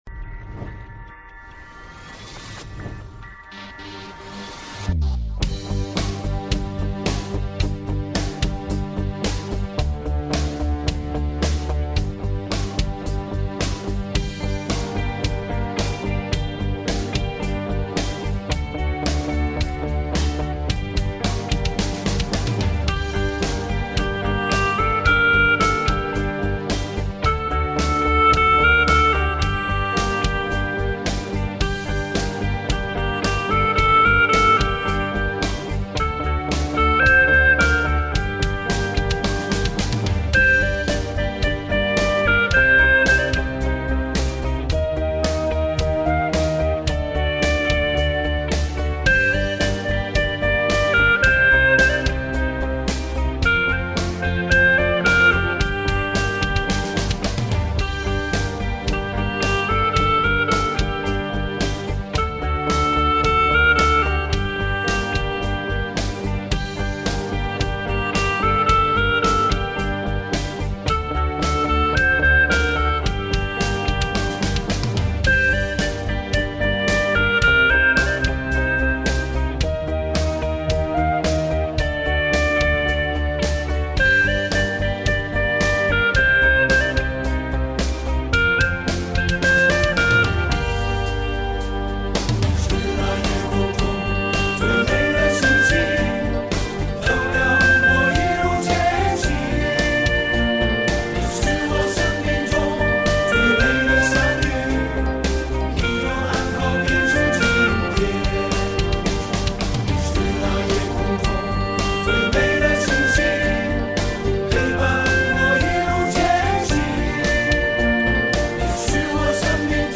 调式 : 降B 曲类 : 流行